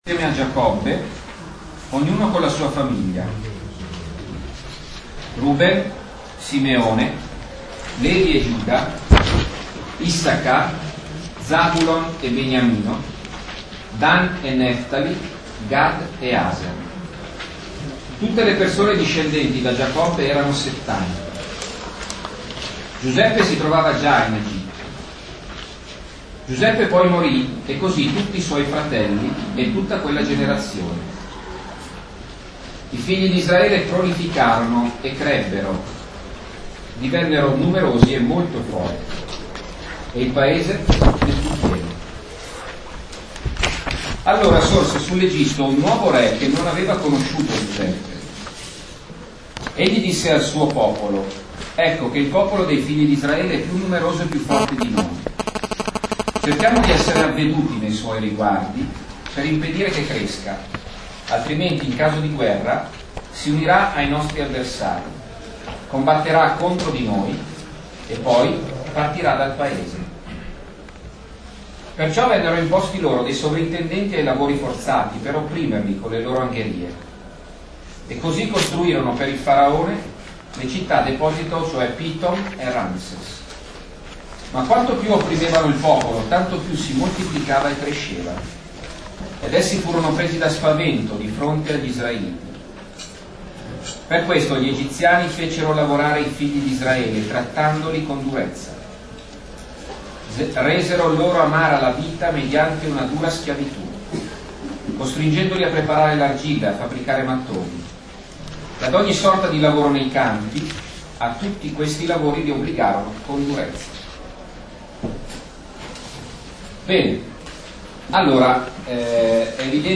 Lectio